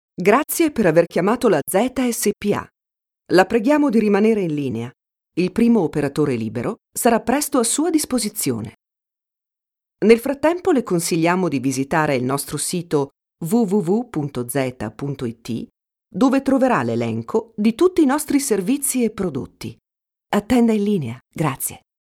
IVR Interactive Voice Response
IVR-Interactive-Voice-Response-SEGRETERIA-.mp3